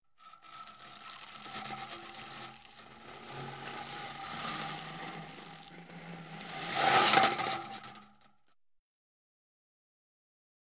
Rainstick | Sneak On The Lot
Rain Stick Device; Left Ch. Bead Drop In Rain Stick, Right Ch. Hollow Impacts Drop.